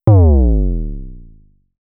Kick